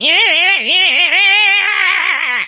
carl_ulti_vo_01.ogg